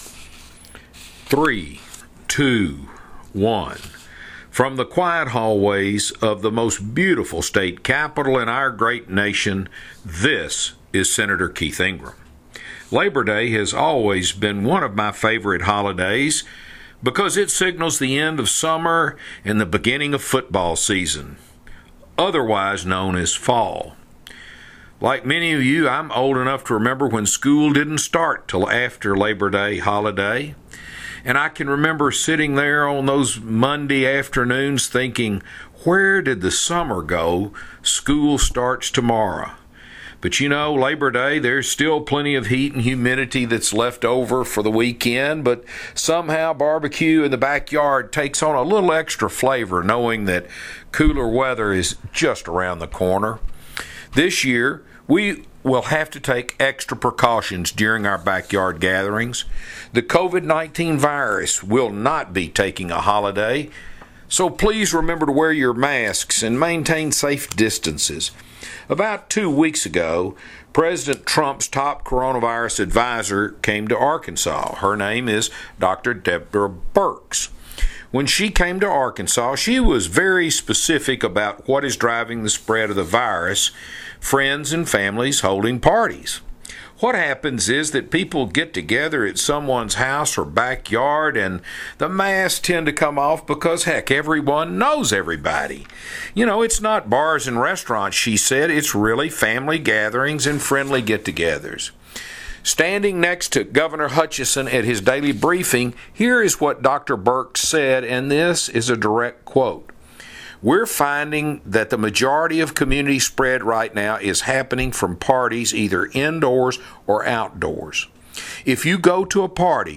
Weekly Address – September 4, 2020 | 2020-09-04T15:51:01.040Z | Sen.